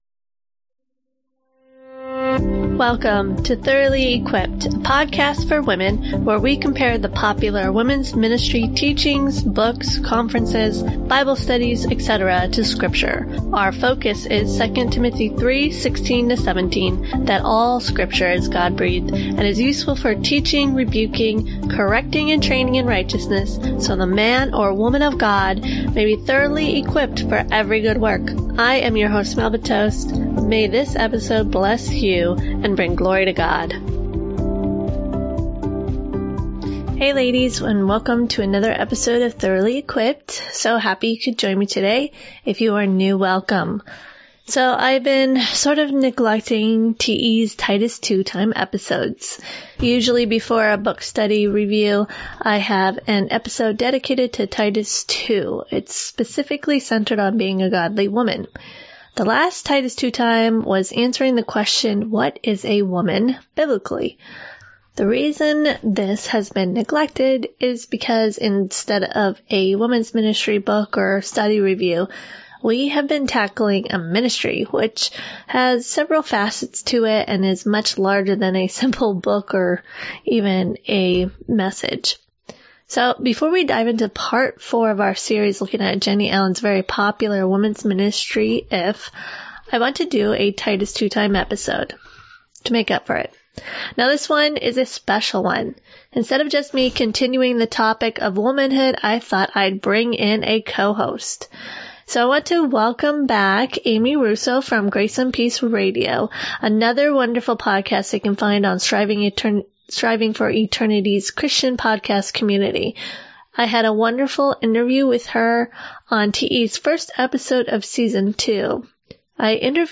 Thoroughly Equipped S.2 Ep.17 *For better audio quality, listen on any of the popular podcasting apps In this Titus 2 Time episode I bring in a co-host